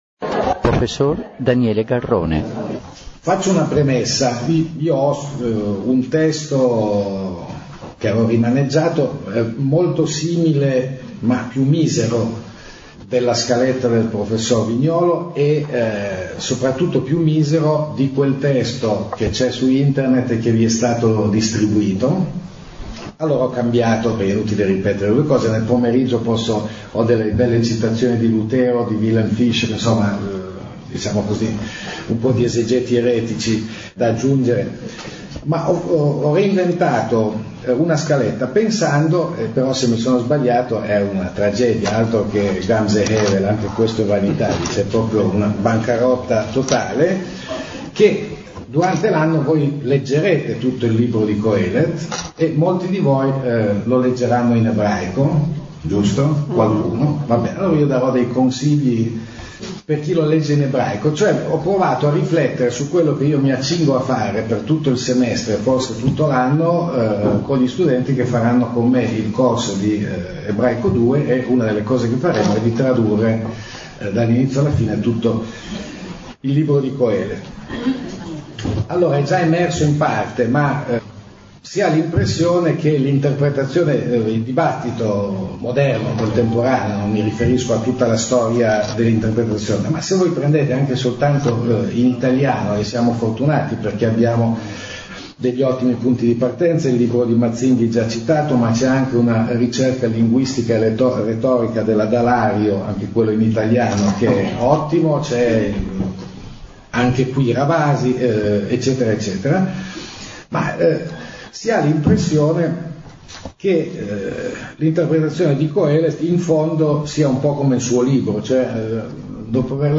Conferenza